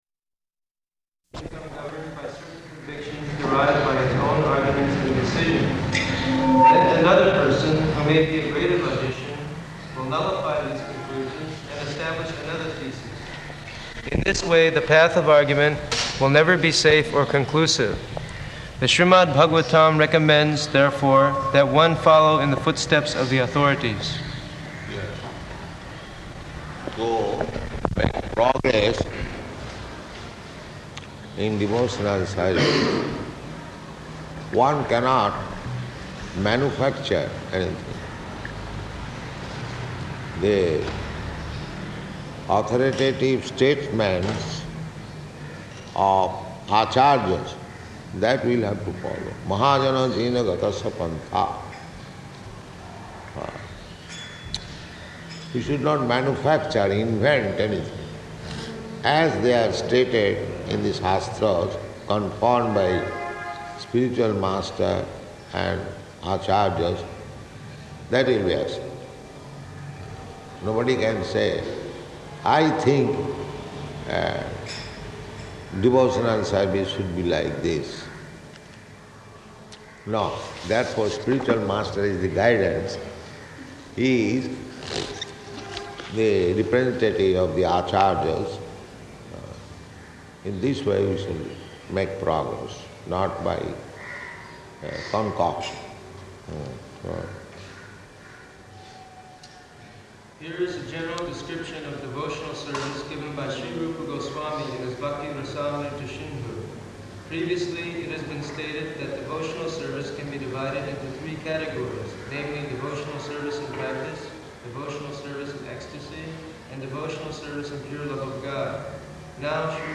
Location: Calcutta